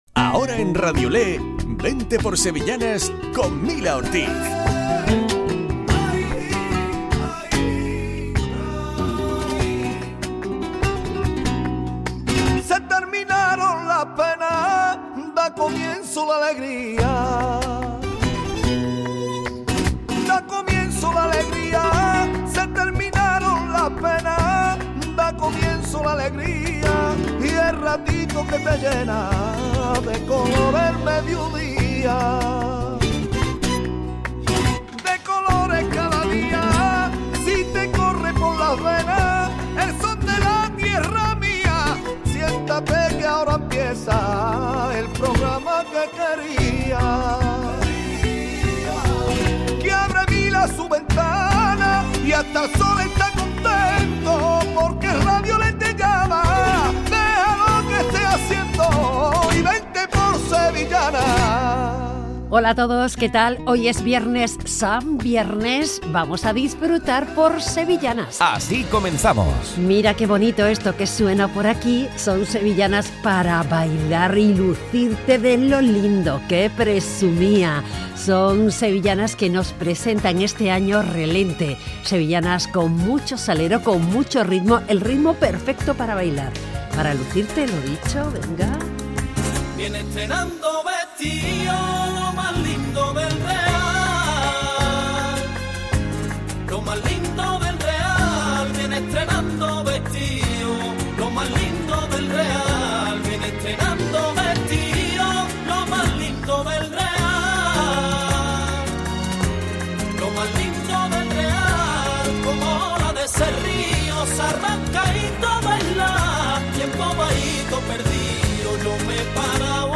VENTE POR SEVILLANAS 03 MARZO 2023: Entrevista
Programa dedicado a las sevillanas.